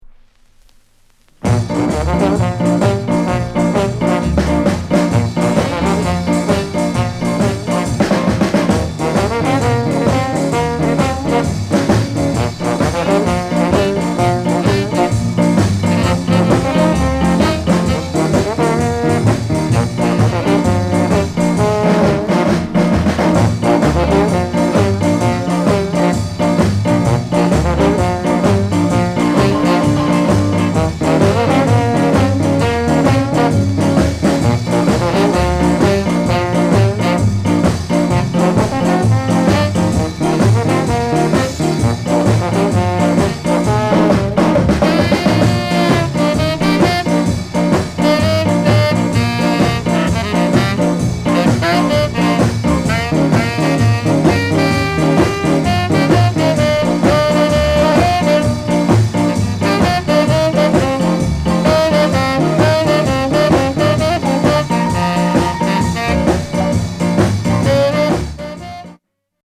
WICKED SHUFFLE